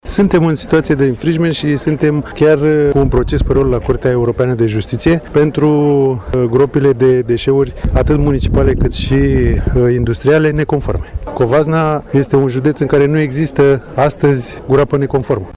Secretarul de stat în Ministerul Mediului, Laurențiu Neculaescu a ţinut să sublinieze că judeţul Covasna este un exemplu pozitiv în materie, în contextul în care ţara noastră se confruntă cu situaţii de infringement pe mediu din partea Comisiei Europene: